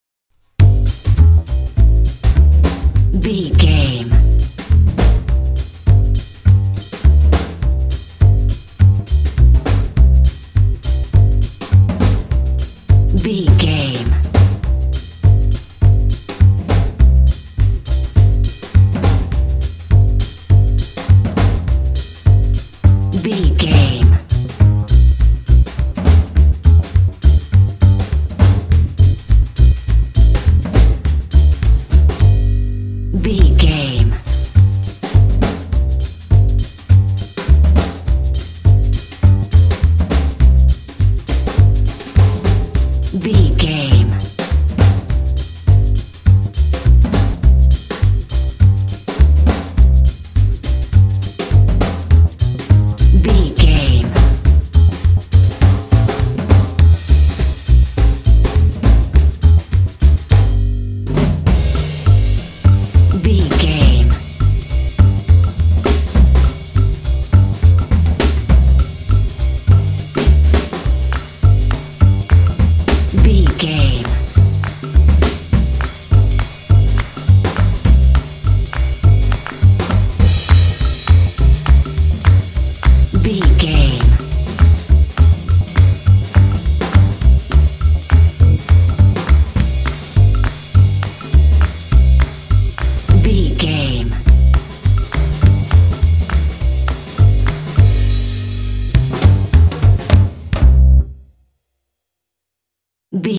Ionian/Major
light
playful
uplifting
calm
cheerful/happy
drums
bass guitar